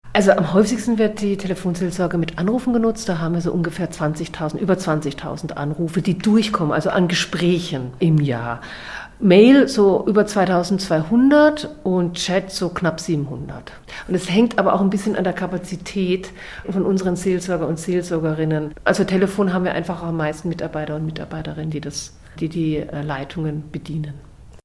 So berichtete die ARD 1960 über die Telefonseelsorge.